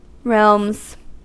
Ääntäminen
Ääntäminen US Haettu sana löytyi näillä lähdekielillä: englanti Käännöksiä ei löytynyt valitulle kohdekielelle. Realms on sanan realm monikko.